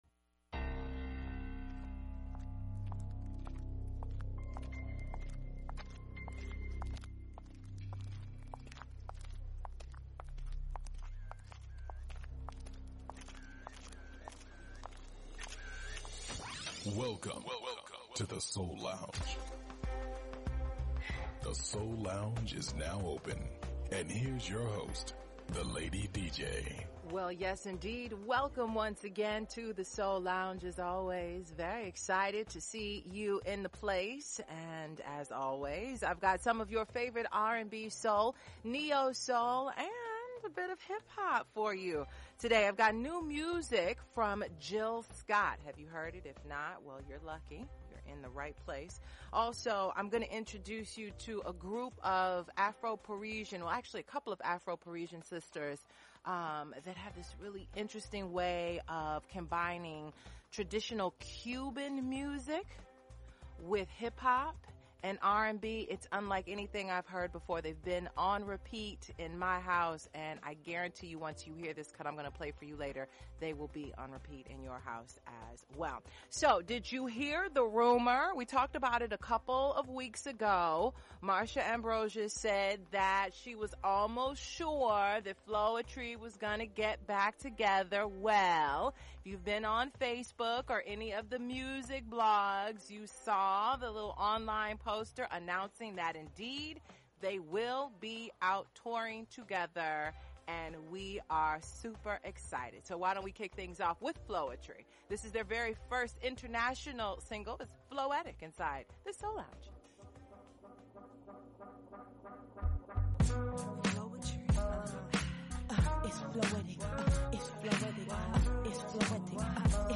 Neo-Soul
conscious Hip-Hop